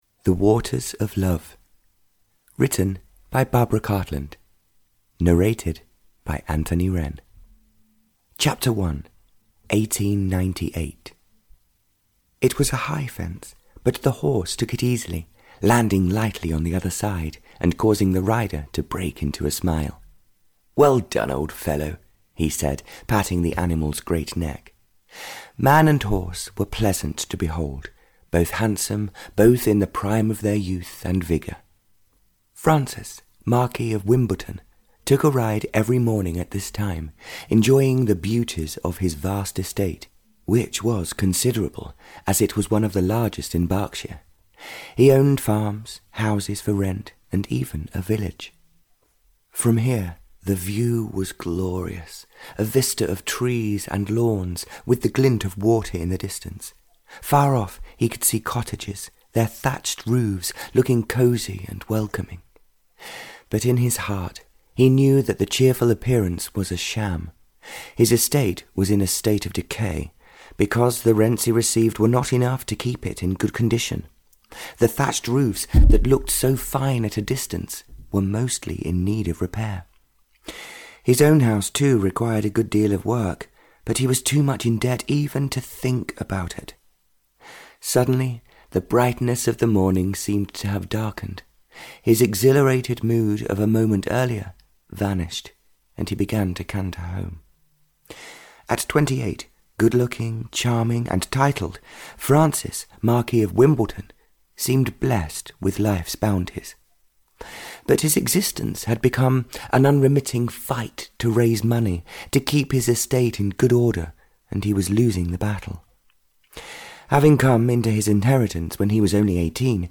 The Waters of Love (EN) audiokniha
Ukázka z knihy